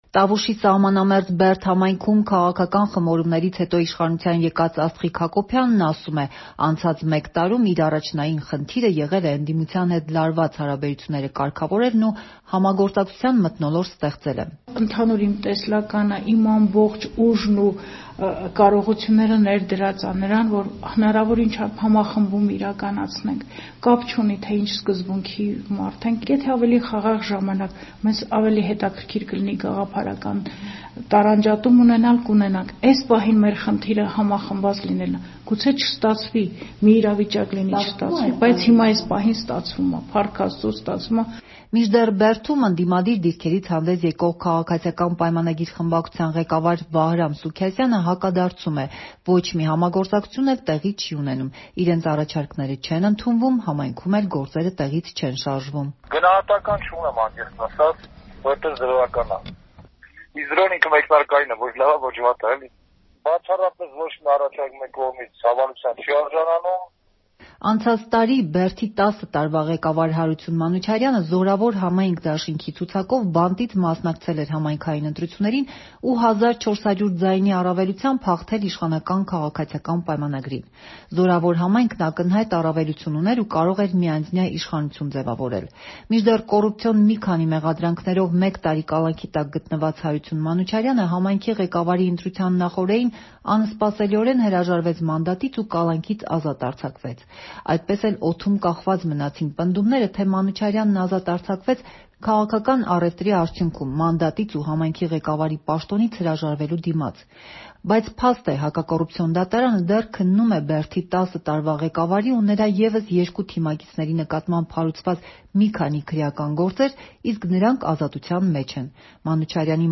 Մանրամասները՝ «Ազատության» ռեպորտաժում.